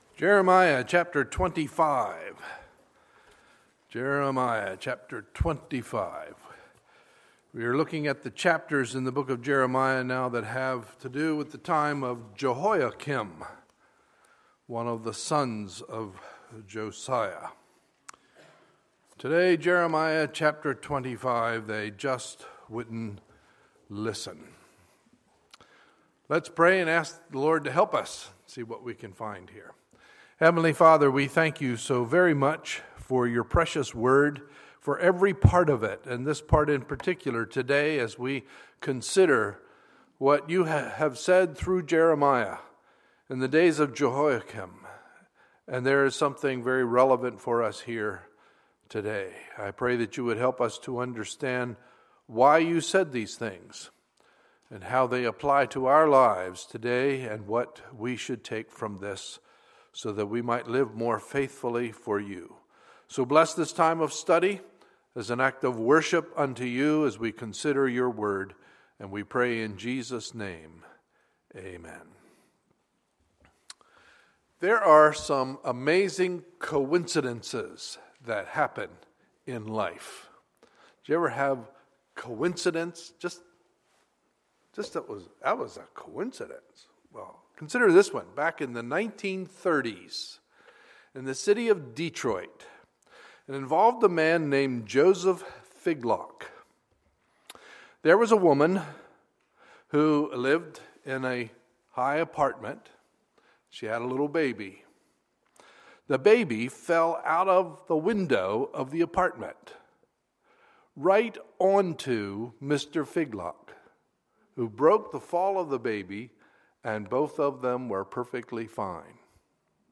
Sunday, July 19, 2015 – Sunday Morning Service